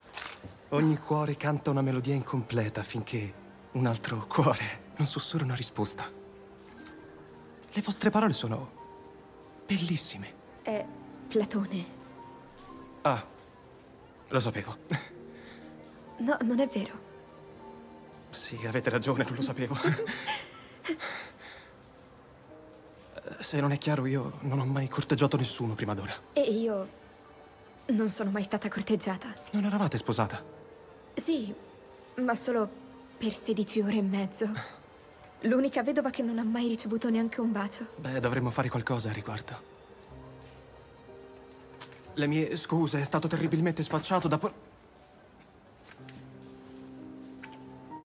nel telefilm "My Lady Jane", in cui doppia Brandon Grace.